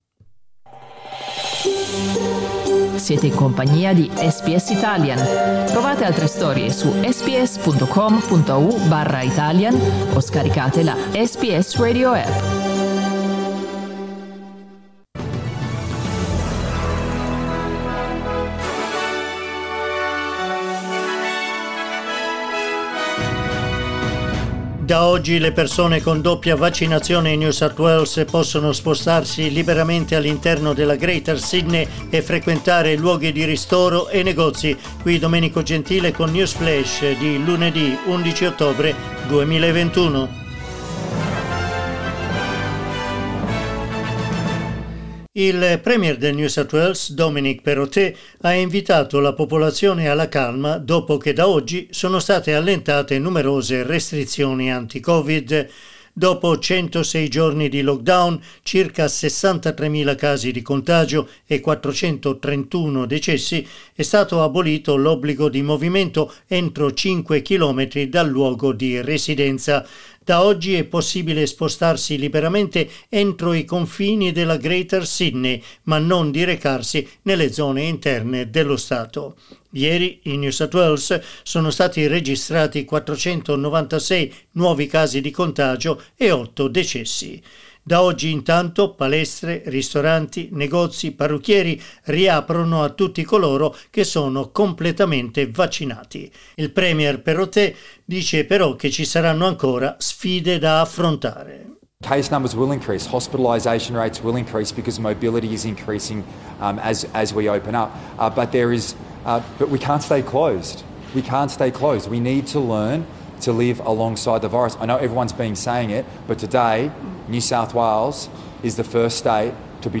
L'aggiornamento delle notizie di SBS Italian